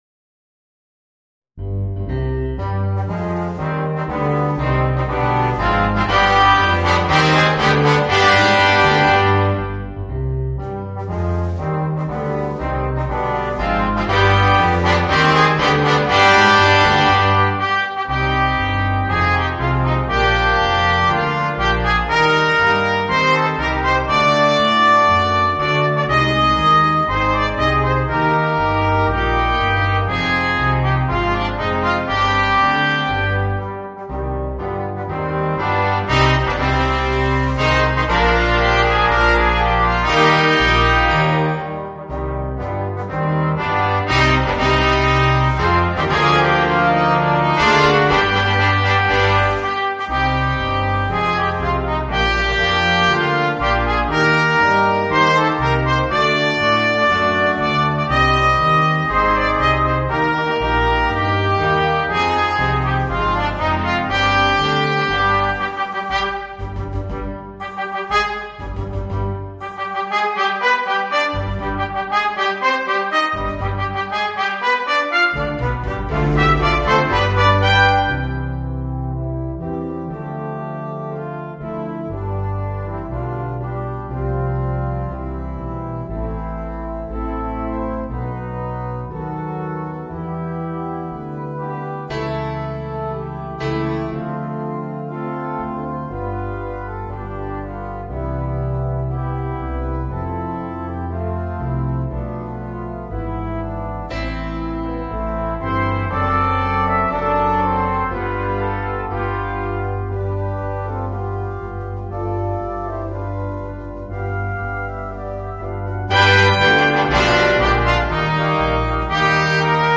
Voicing: 5 - Part Ensemble